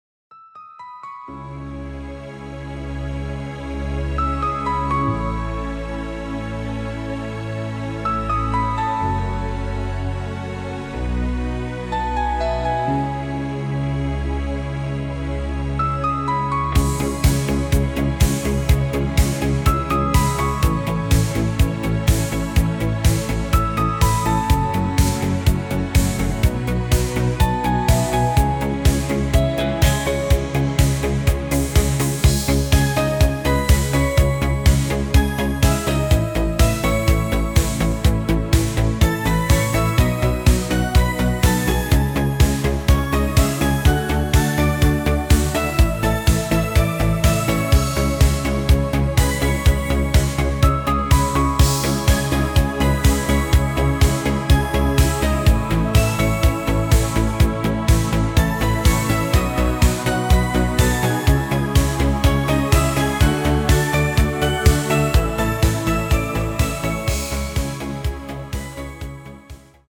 KI erzeugt mit Synthesizer Sound
Etwas außergewöhnlicher Sound im Stil von Synthesizer Musik.